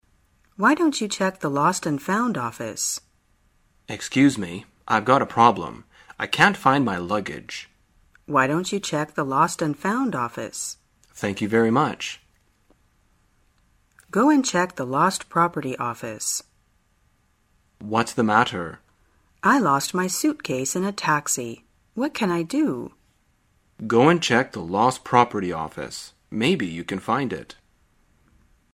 旅游口语情景对话 第340天:如何提意见